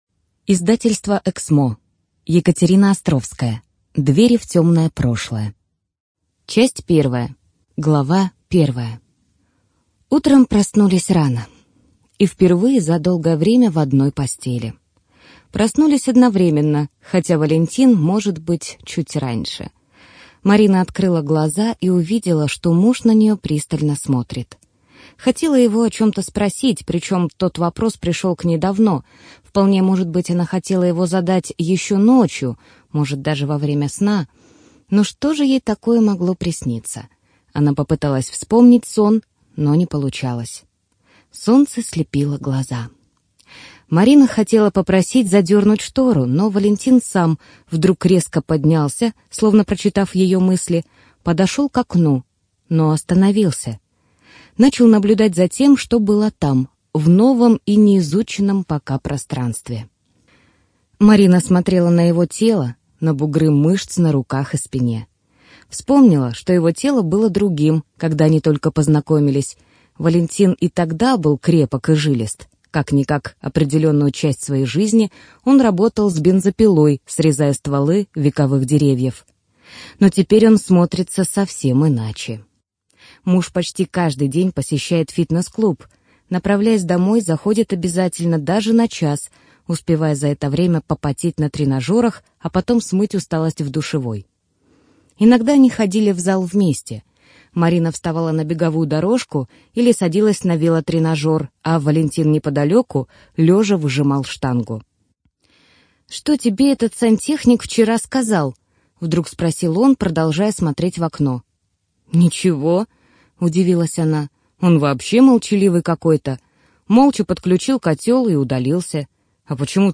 Студия звукозаписиЭКСМО